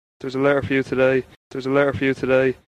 LETTER with medial [h] (local Dublin speaker)
DUB_Medial_T-to-H_(local_speaker).mp3